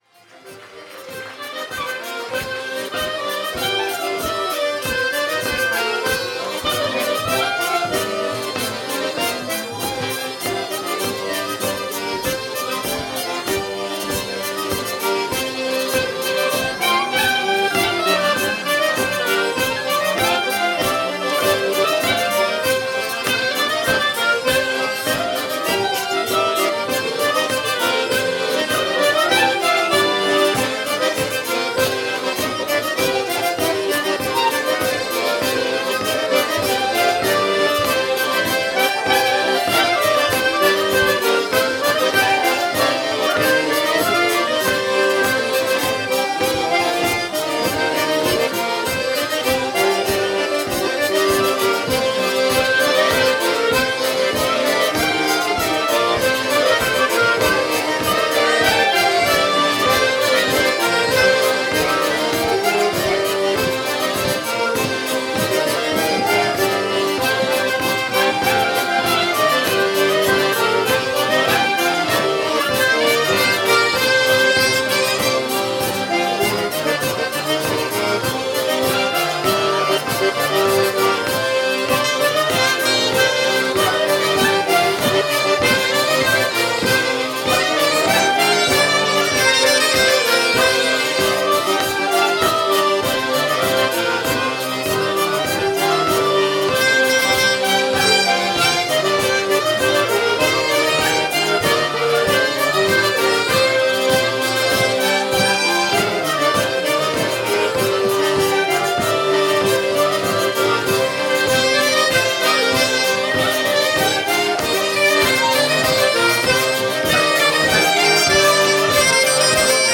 :mp3:2013:soiree_stagiaires